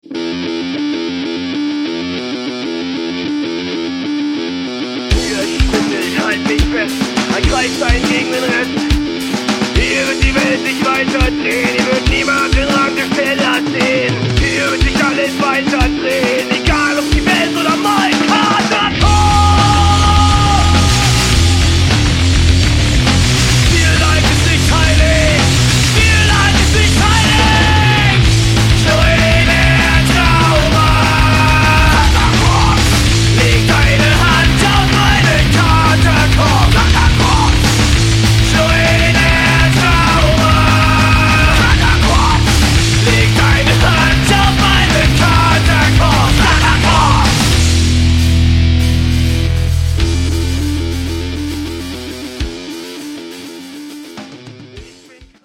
Abgedrehtes Riffing und deutscher Text.